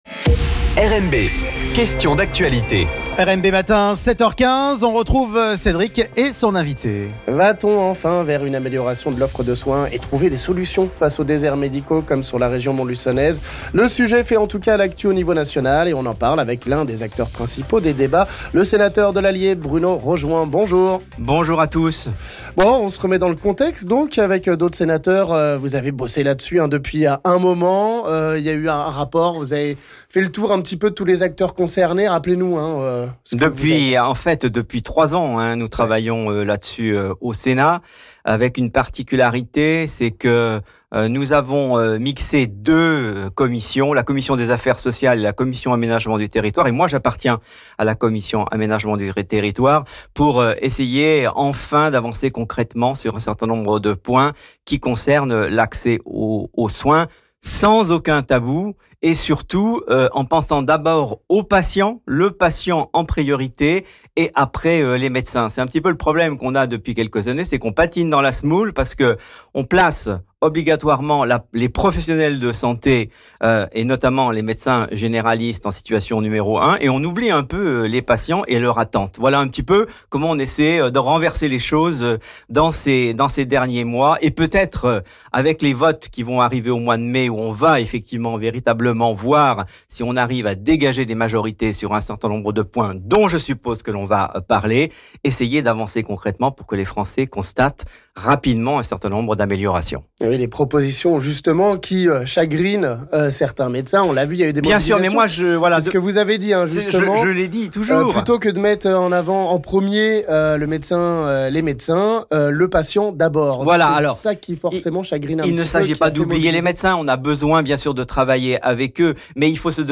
Le s�nateur de l'Allier Bruno Rojouan nous parle des propositions bient�t d�battues sur l'acc�s aux soins et l'installation des m�decins - L'Invit� du Jour